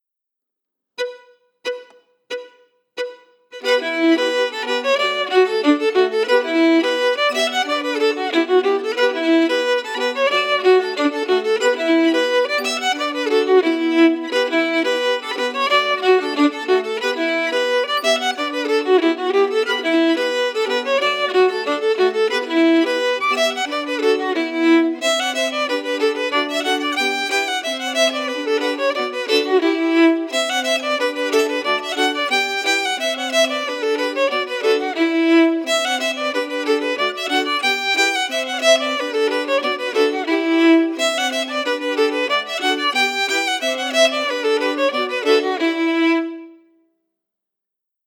Key: E Dorian
Form: Reel
Melody emphasis
Source: Trad.